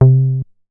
MoogAttack 006.WAV